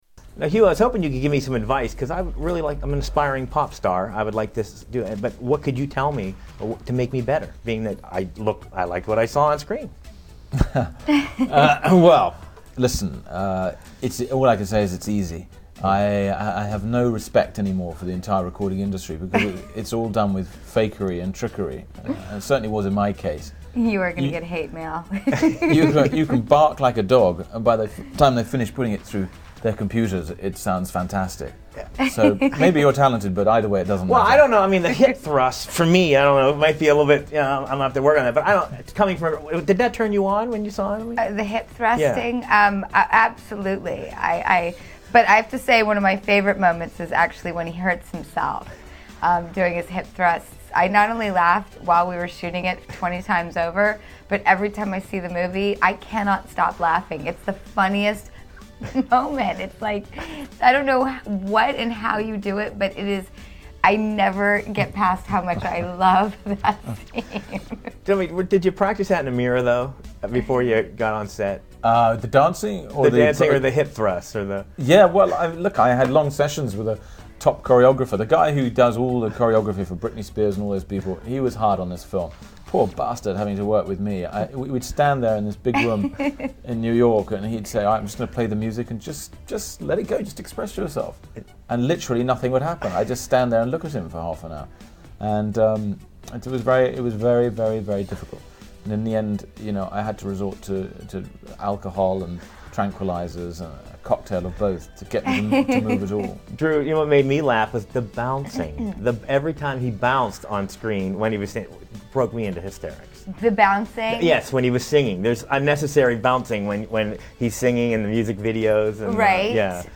Hugh Grant and Drew Barrymore interview